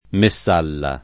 Messalla
vai all'elenco alfabetico delle voci ingrandisci il carattere 100% rimpicciolisci il carattere stampa invia tramite posta elettronica codividi su Facebook Messalla [ me SS# lla ] o Messala [ me SS# la ] pers. m. stor.